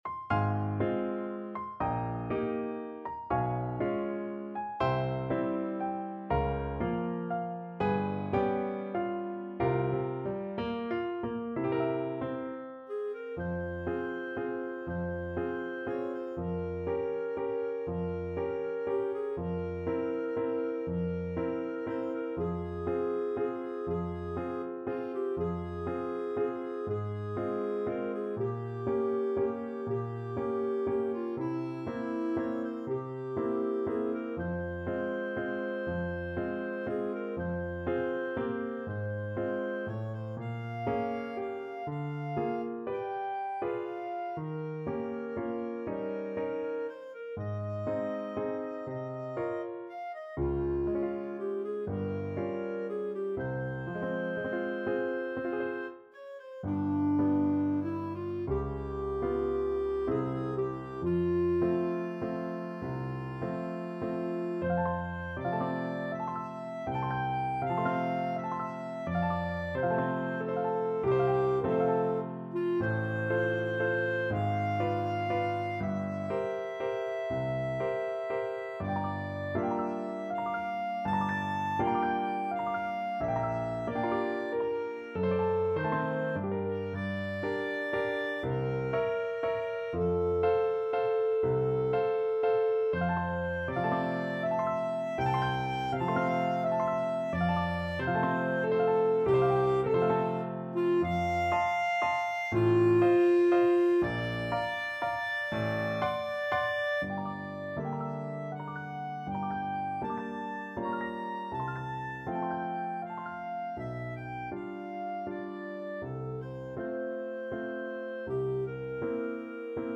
~ = 120 Lento